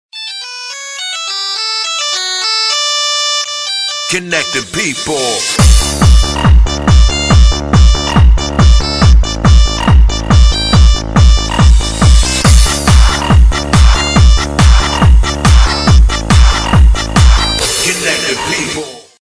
Категория: Короткие мелодии для SMS